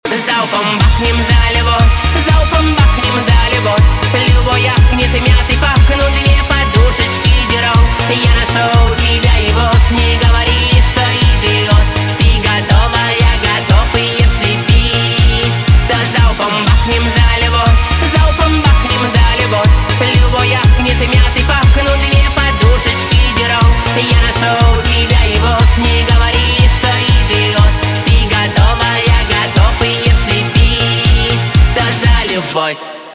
поп
диско